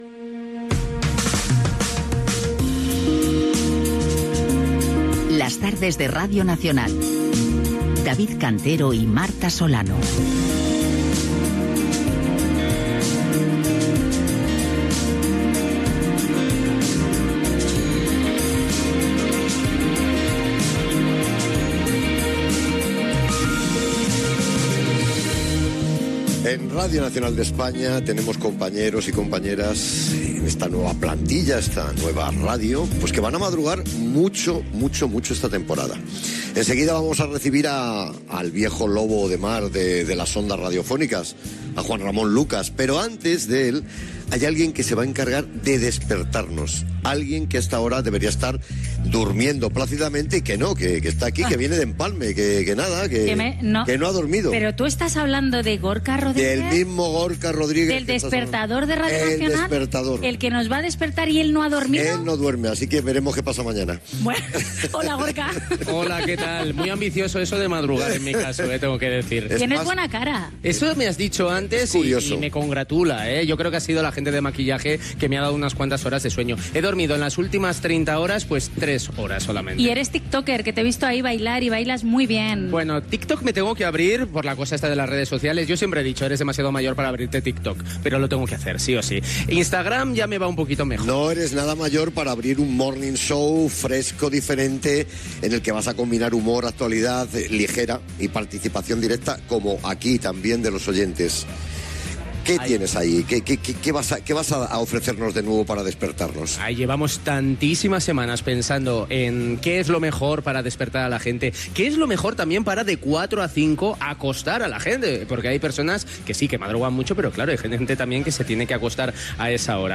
Presentació de la nova programació de RNE per la temporada 2025-2026 des d'un hotel de Madrid.
Entrevista a Isabel Gemio del programa "El último tren".
Entrevista a Juan Ramón Lucas de "Las mañanas de RNE",
Entreteniment